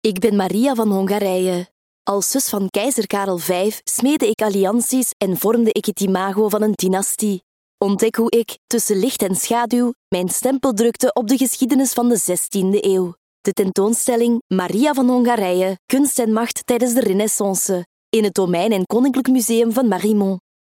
Junge, Verspielt, Zuverlässig, Freundlich
Audioguide